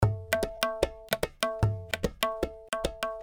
Tabla loops 150 bpm
This is an Indian tabla drum loops (scale A), playing a variety of styles.
Played by a professional tabla player.
The tabla was recorded using one of the best microphone on the market, The AKG C-12 VR microphone . The loops are mono with no EQ, EFFECT or DYNAMICS, but exported stereo for easy Drop and play .